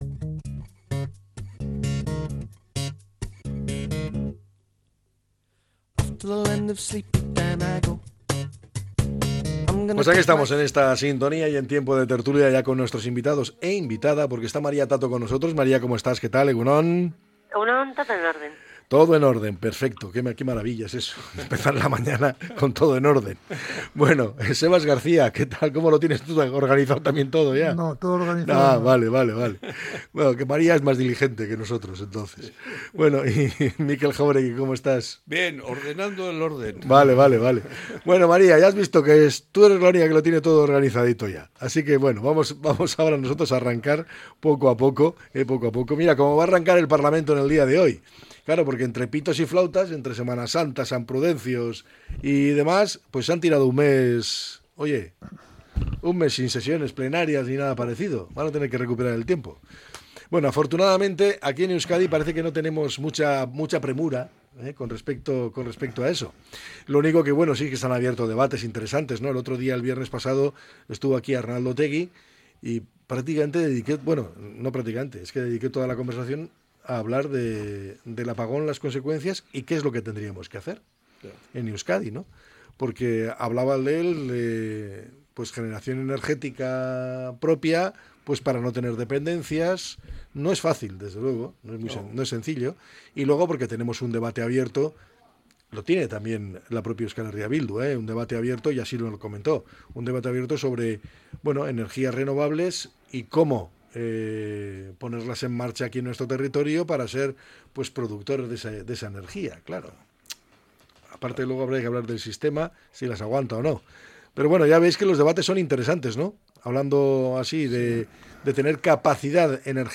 La tertulia 05-05-25.